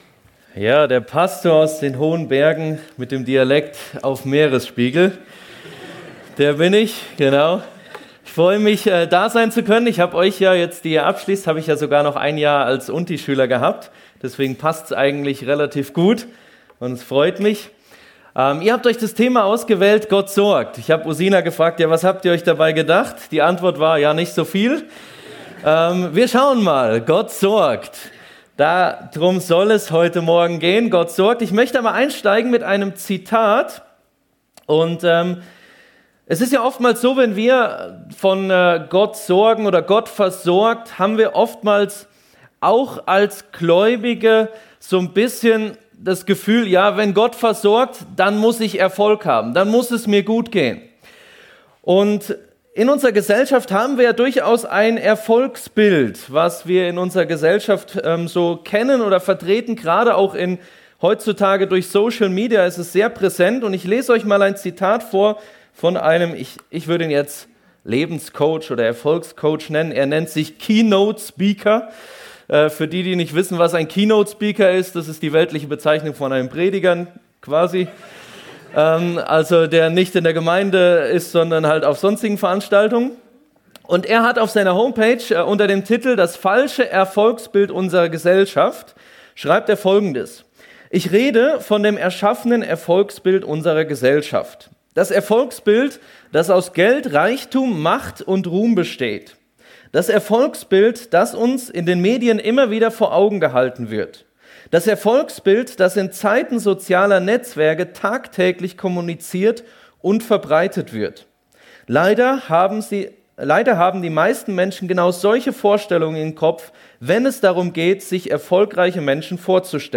Gott sorgt! ~ FEG Sumiswald - Predigten Podcast